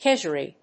音節ked･ger･ee発音記号・読み方kéʤərìː|kèʤríː